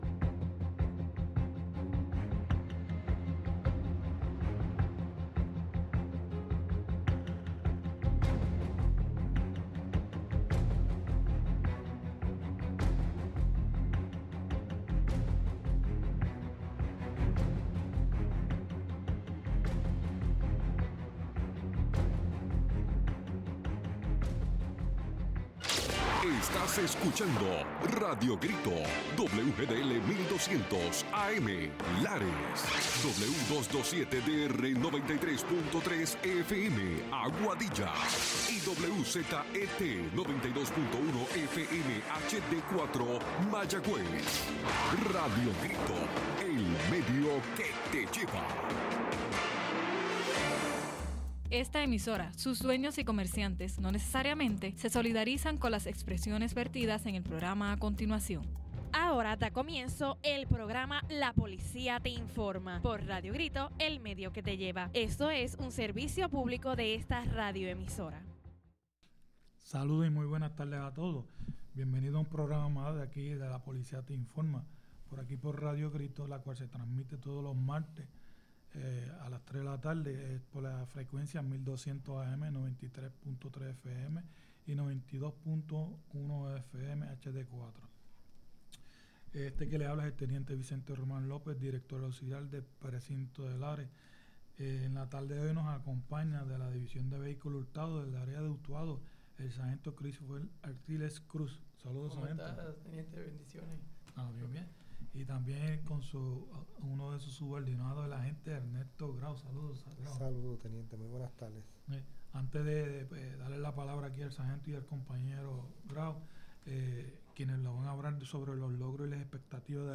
junto a otros compañeros de la policía de Puerto Rico nos hablan sobre el tema de vehículo hurtados.